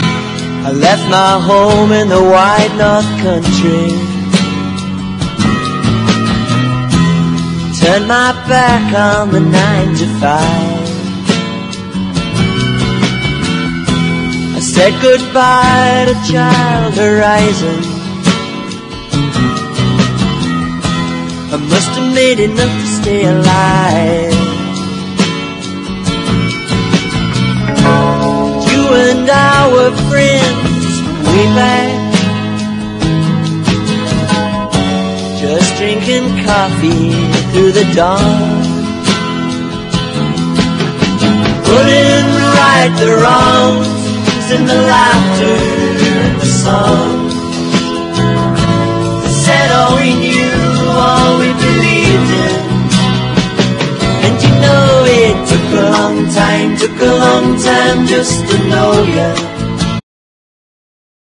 瑞々しいメロディーが輝く名曲揃いのモダン・ポップ/シンセ・ポップ傑作1ST！